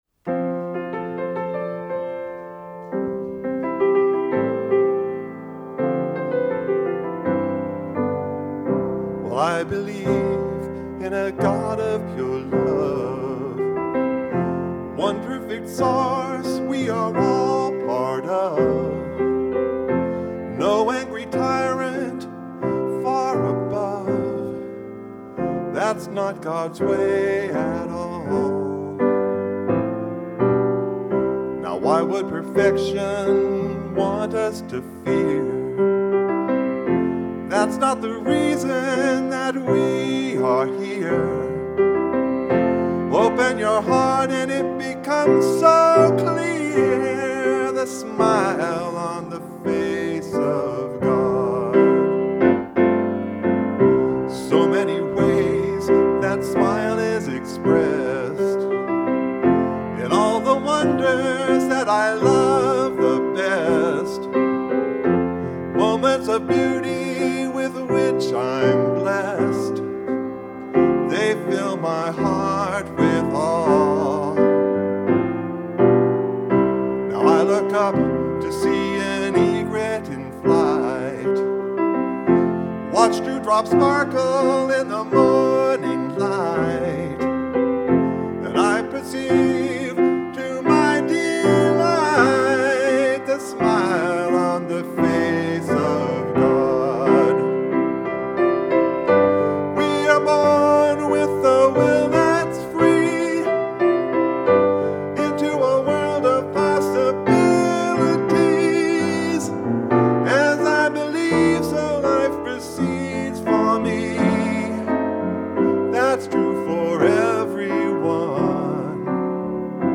Recorded live on 1/23/2011 at the Center for Spiritual